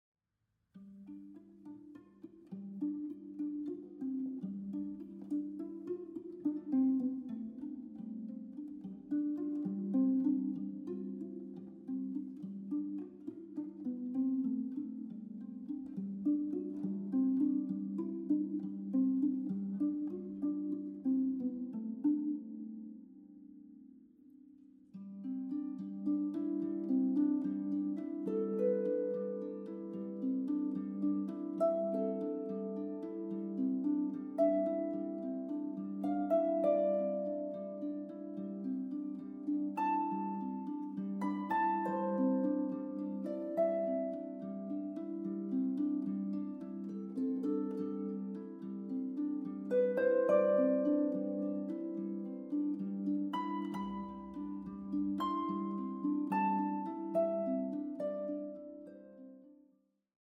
Zeitgenössische Musik für Harfe
Harfe